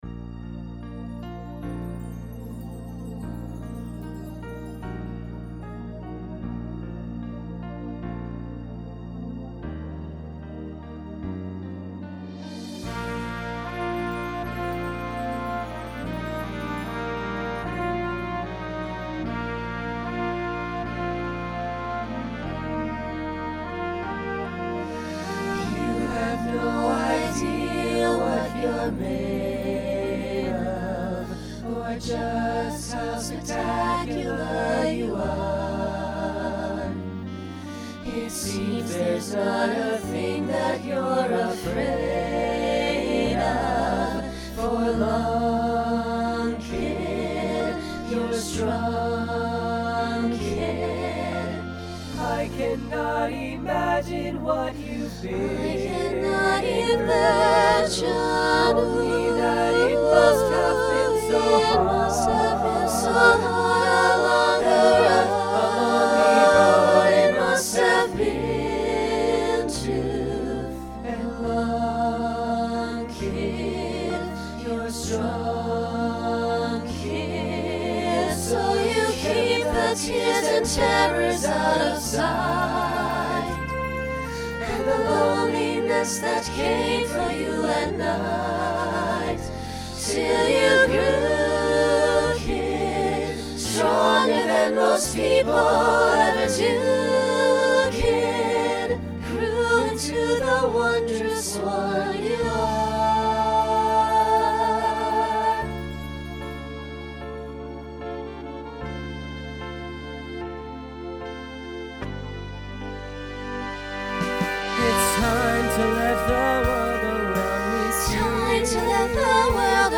Genre Broadway/Film
Ballad , Solo Feature Voicing SATB